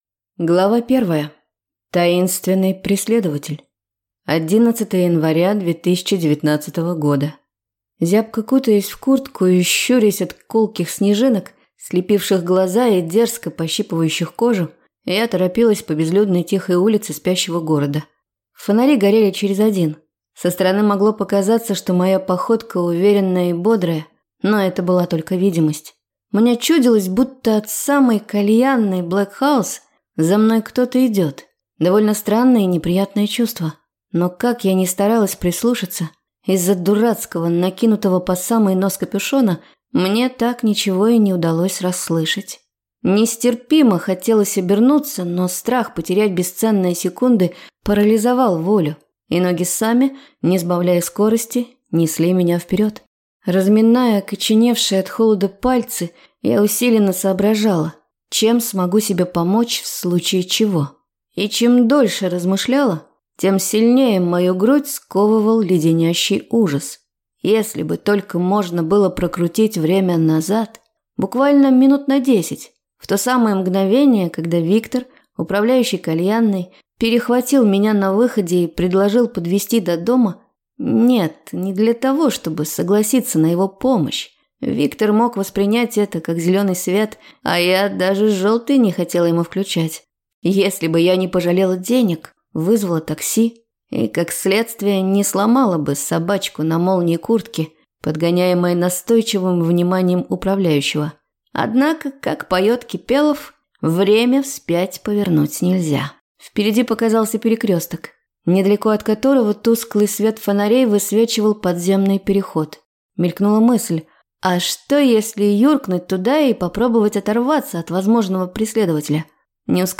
Аудиокнига Ангельская пыль | Библиотека аудиокниг
Прослушать и бесплатно скачать фрагмент аудиокниги